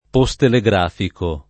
[ po S tele g r # fiko ]